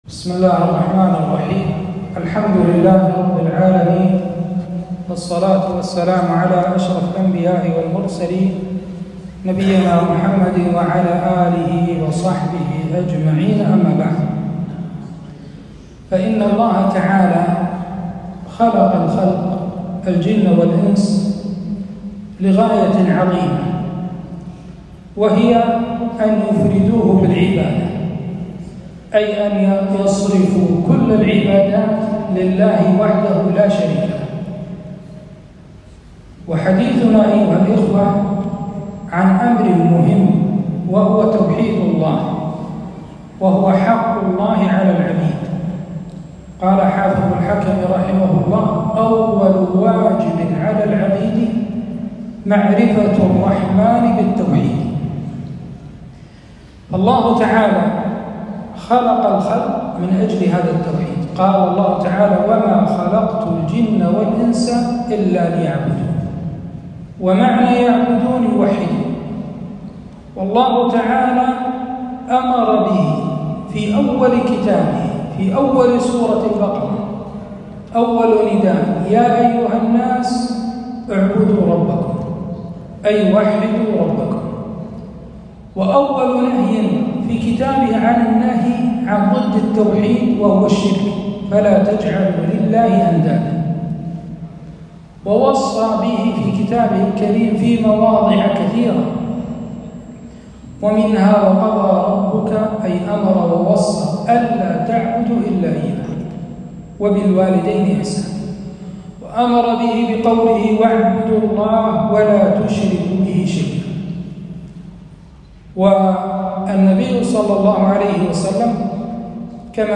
محاضرة - فضائل التوحيد وأهميته